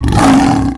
lion.wav